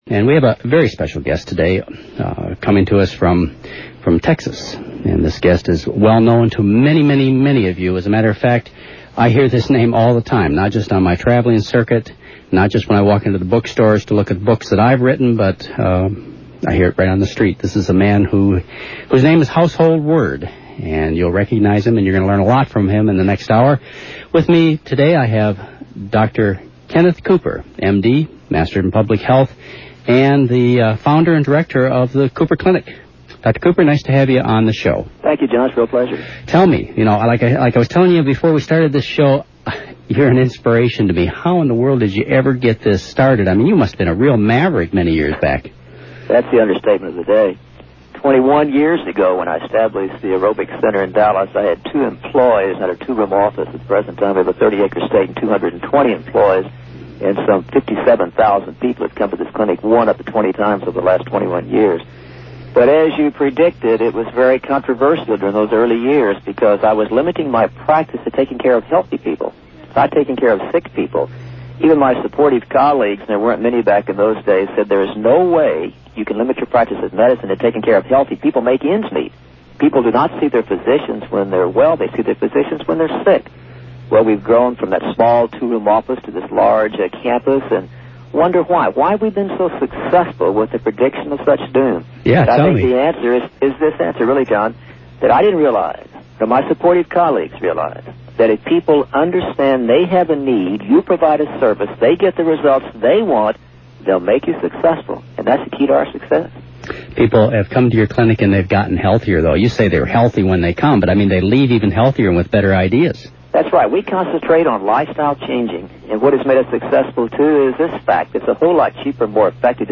In this 1991 interview, Dr. McDougall talks with Kenneth Cooper, MD, founder and director of Texas’ Cooper Clinic. Cooper’s philosophy is that it’s much cheaper and effective to maintain good health than to regain it once it’s lost.
Note: This taped interview from “Your Good Health” (12/10/91), hosted by Dr. McDougall has been edited.